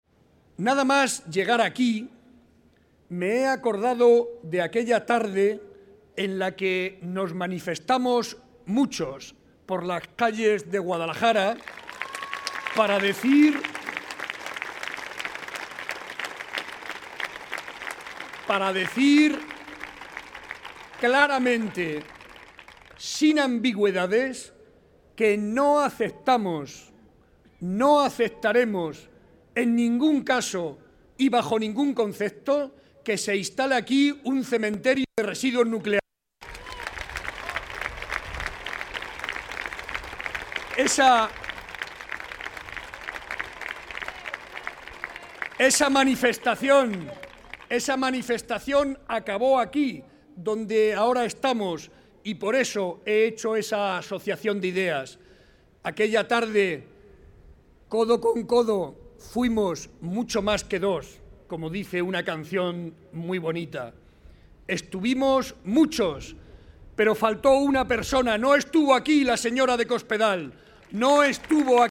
Audio Barreda mitin Guadalajara 2